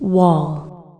wall.mp3